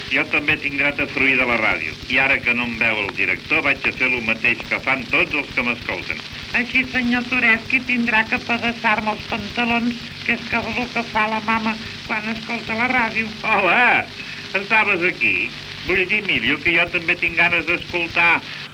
Diàleg entre el senyor Toresky i en Miliu.